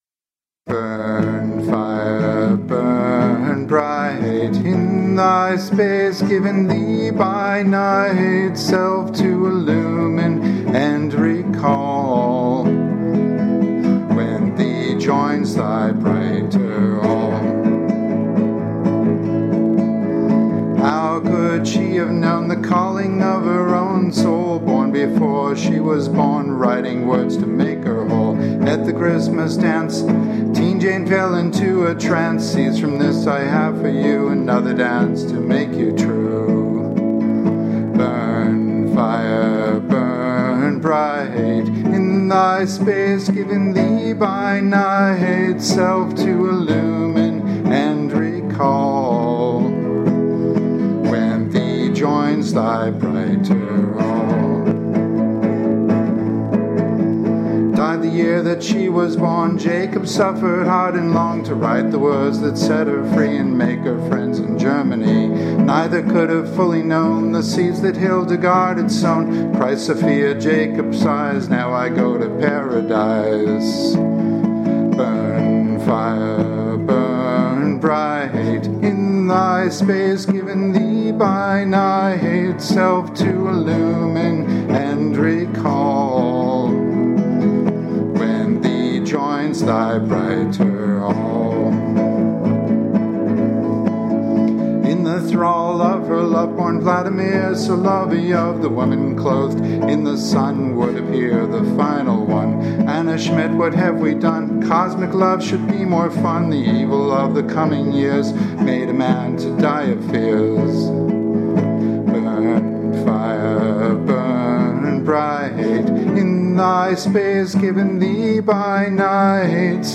a mysterious love song...